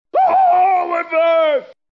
Play, download and share Oh my leg grito hombre original sound button!!!!
oh-my-leg-grito-hombre.mp3